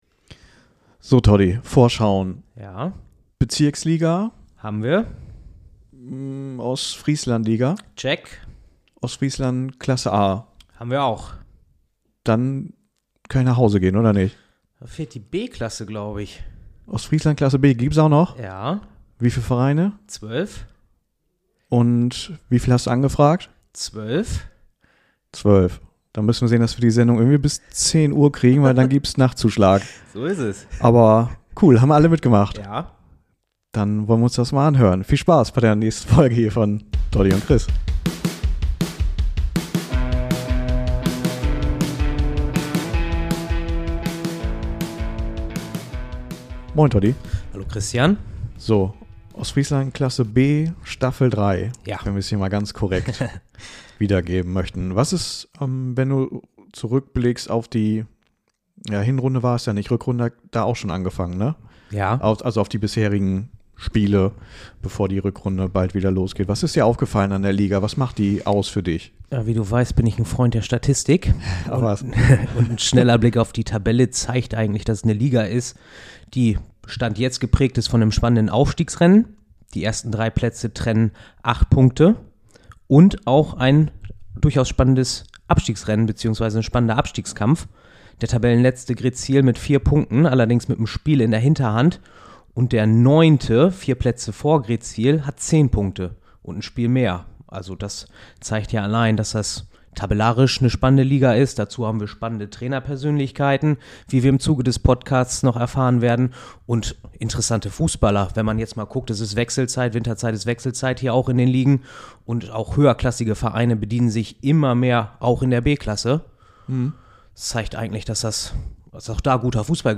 Und da die Ostfrieslandklasse B generell nicht so im Fokus steht, wie etwa die höherklassigen hiesigen Ligen, haben wir gleich allen zwölf Vereinen, beziehungsweise ihren Vertretern, unser Mikrofon unter die Nase gehalten. Vorhang auf: Hier ist unsere Sonderepisode zur Ostfrieslandklasse B – viel Spaß damit!